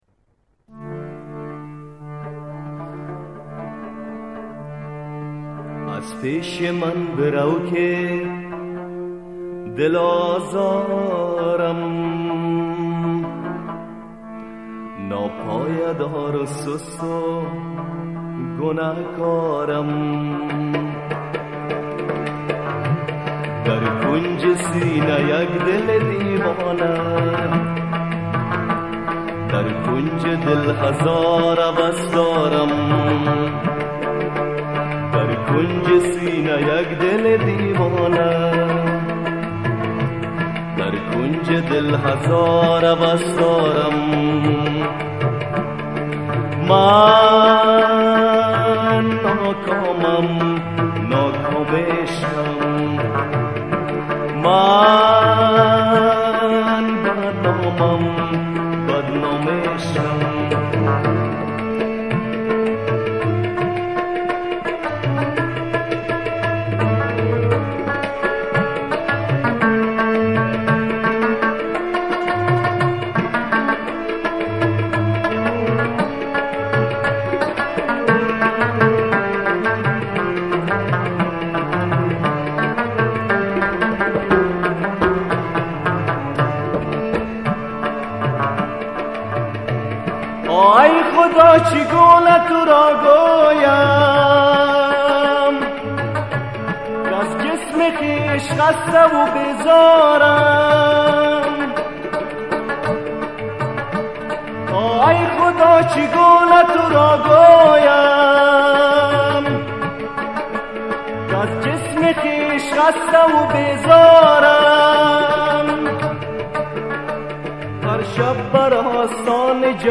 хонандаи афғонистонӣ